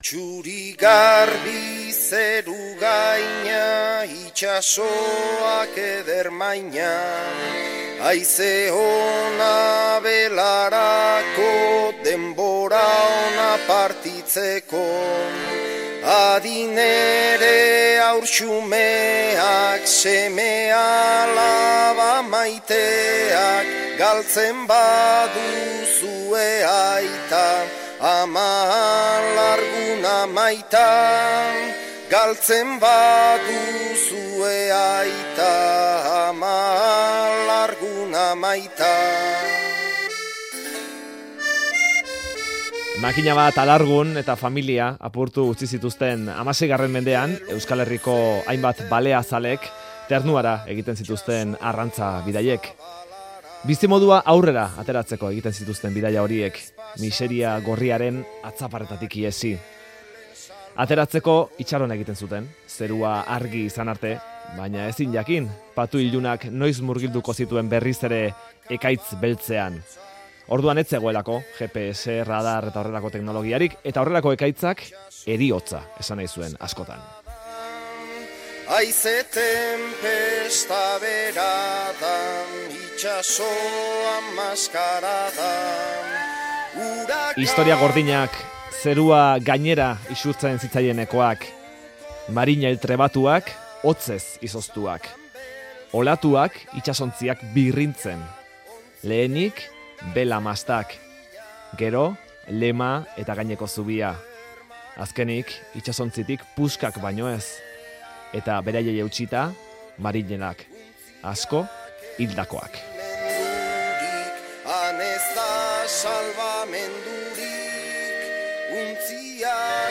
Audioa: 1798ko bertsoei musika jarri die Easo abesbatzak